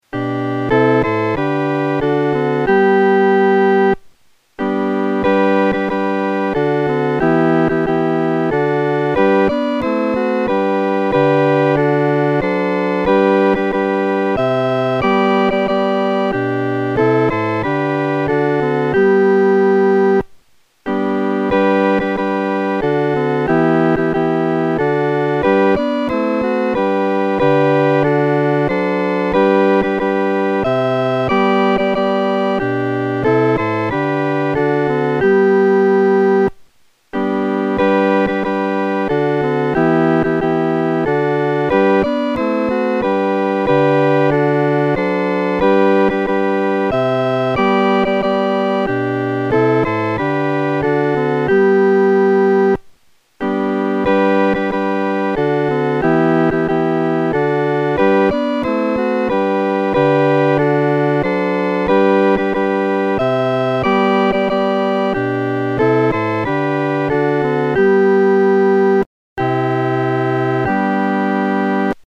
四声